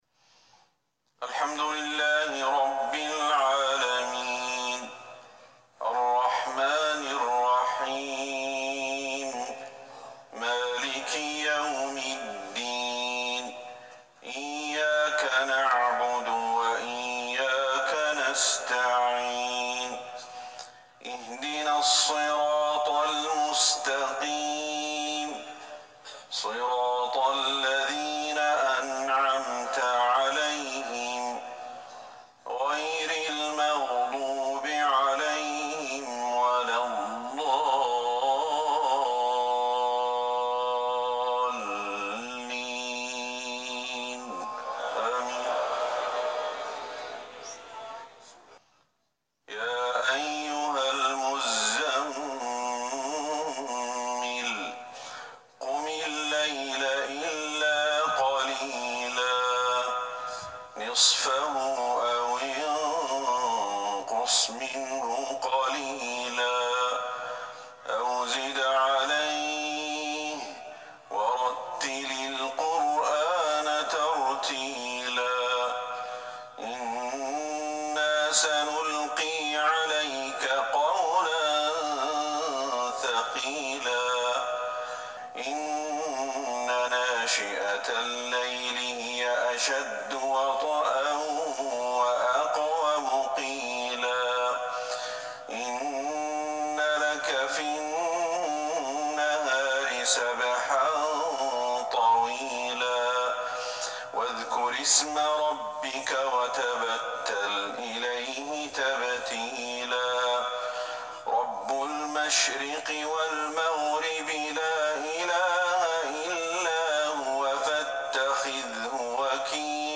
صلاة العشاء ١٨ جمادى الاولى ١٤٤١هـ سورة المزمل Evening prayer 6-1-2020 from Surat Al-Muzammil > 1441 هـ > الفروض